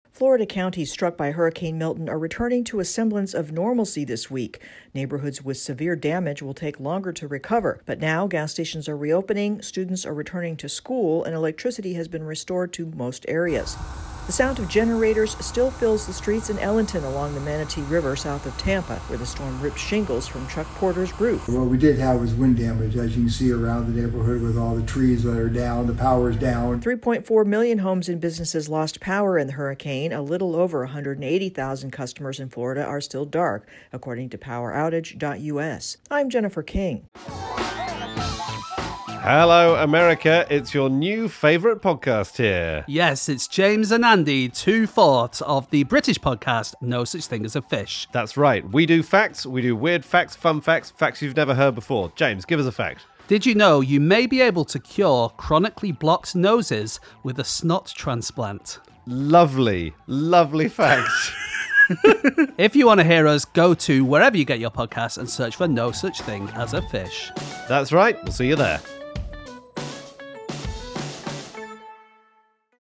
Recovery is underway in Florida, after the one-two punch of Hurricanes Helene and Milton. AP correspondent